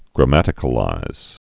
(grə-mătĭ-kə-līz)